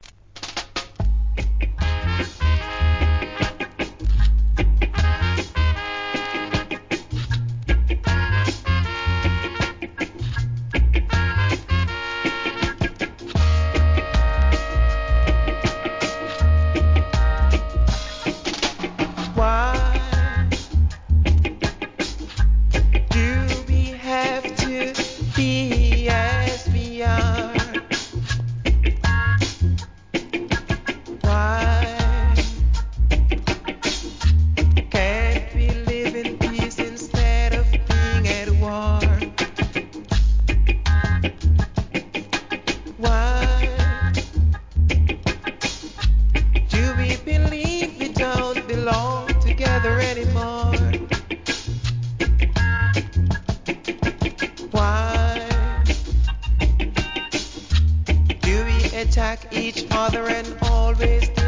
REGGAE
心地よいベース＆ギターRHYTHMで素晴らしいVOCALです!